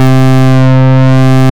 Index of /90_sSampleCDs/Trance_Explosion_Vol1/Instrument Multi-samples/Wasp Bass 1
C3_WaspBass_1.wav